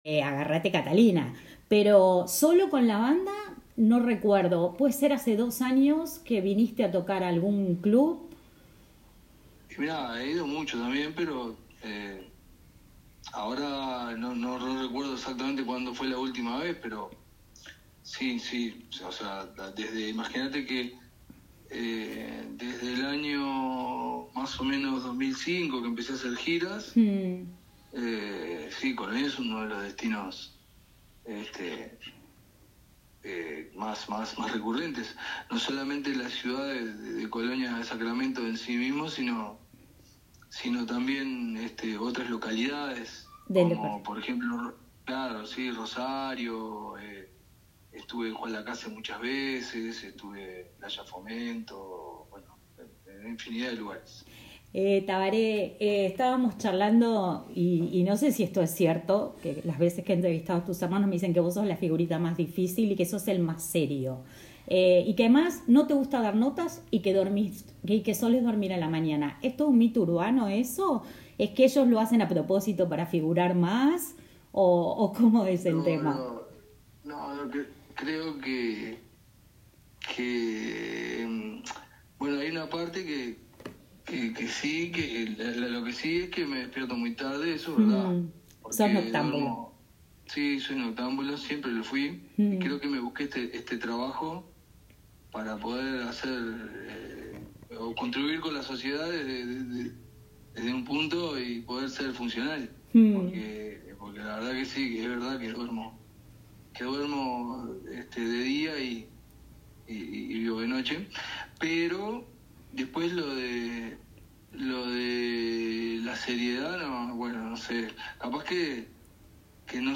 En una entrevista exclusiva para el programa La Matina de Radio Viva 96.3 FM de Colonia, el polifacético cantautor conversó